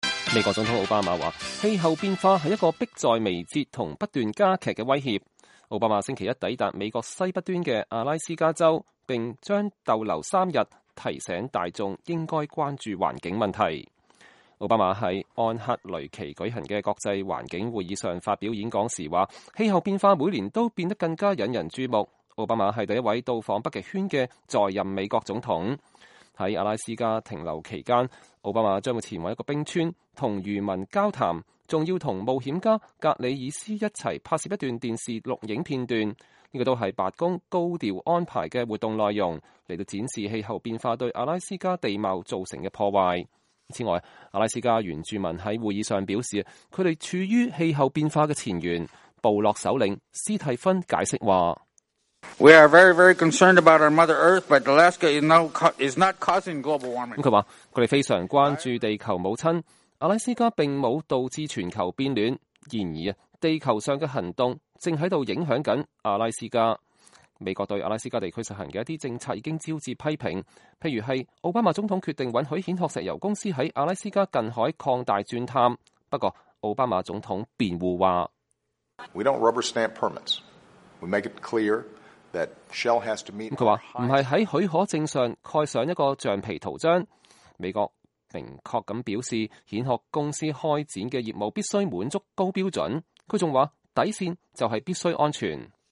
奧巴馬(右)在安克雷奇舉行的國際環境會議上發表演講
2015-09-01 美國之音視頻新聞:奧巴馬:氣候變化威脅迫在眉睫並不斷加劇